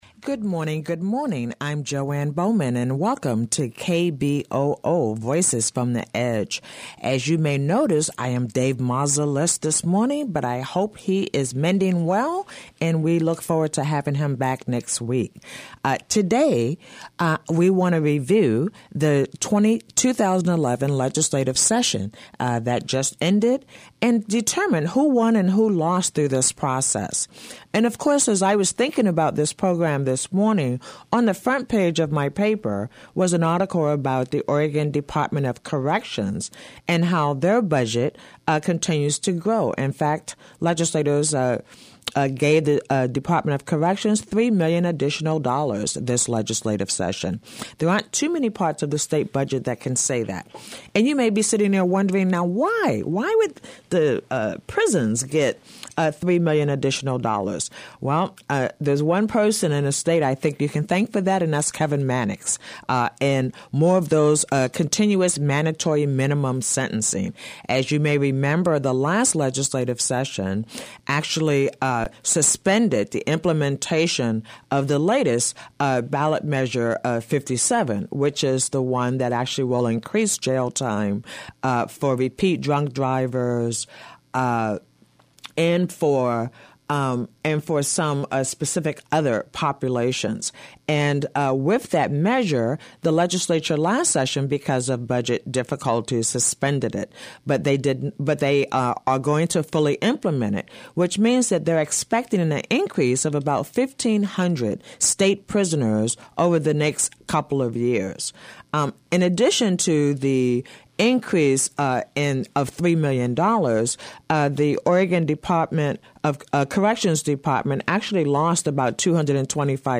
Host Jo Ann Bowman conducts a review of the recent 2011 Oregon legislative session. Tax payers are funding more for prisons, and less for just about everything else -- we can thank Kevin Mannix and the passage of his measure 57 for mandatory sentences.